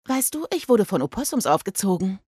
The amusing winter games feature the original German voices of Sid, Manny, Diego, and Ellie!